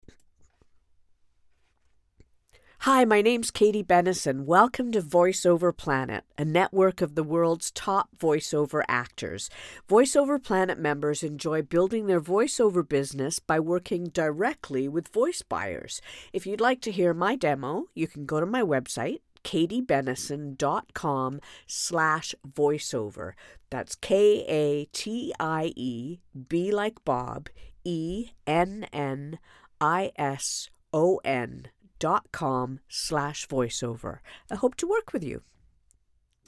canadian, authentic british dialects
Middle Aged